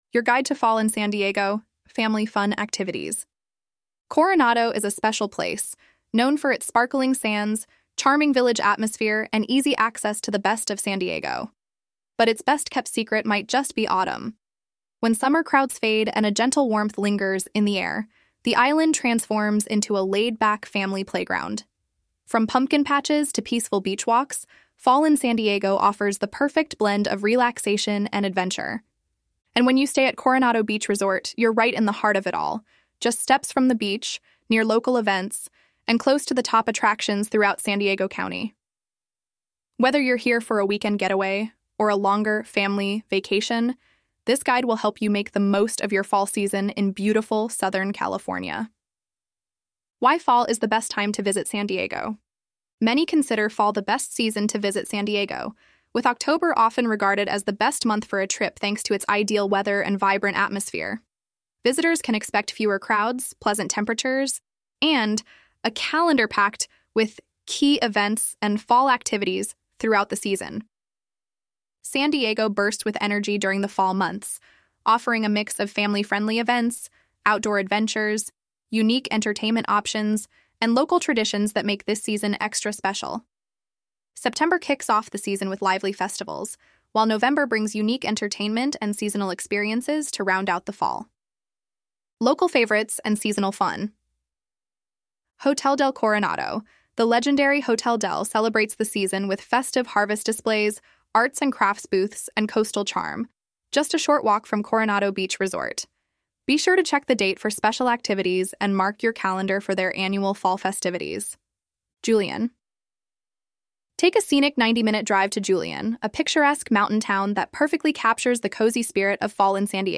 ElevenLabs_Untitled_project-3.mp3